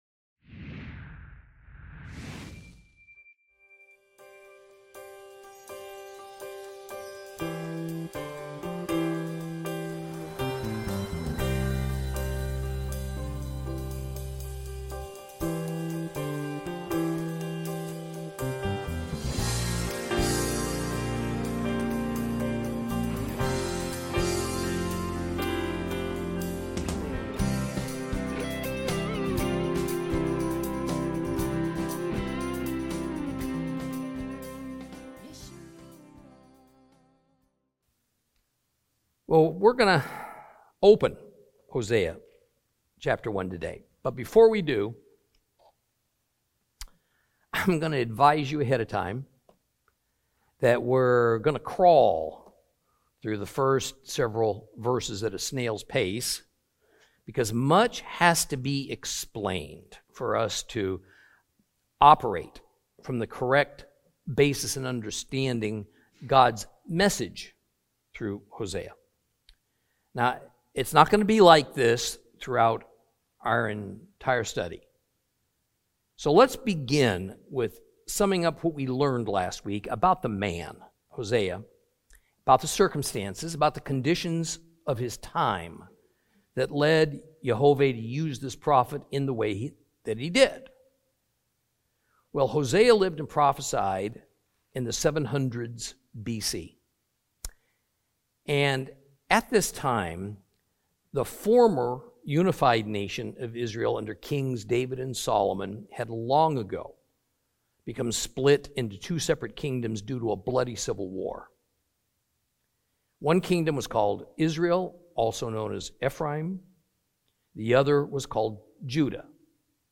Teaching from the book of Hosea, Lesson 2 Chapter 1.